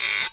1ST-GUIRO -R.wav